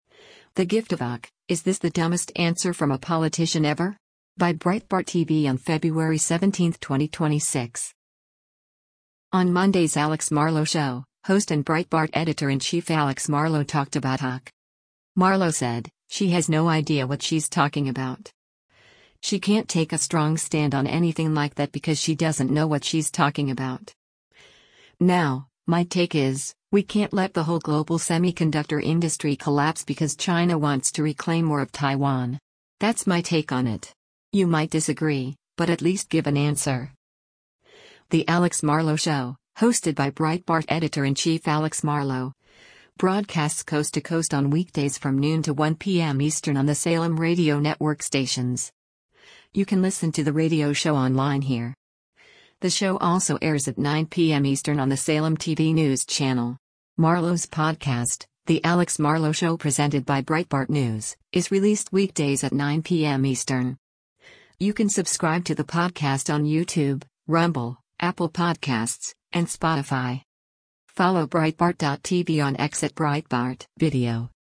On Monday’s “Alex Marlow Show,” host and Breitbart Editor-in-Chief Alex Marlow talked about AOC.